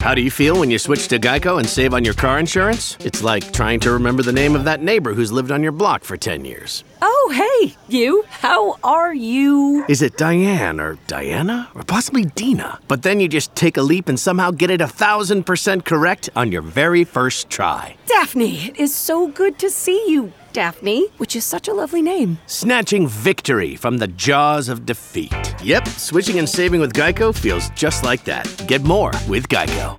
All of our contracted Talent have broadcast quality home recording studios.
Commercial Demo audio.mp3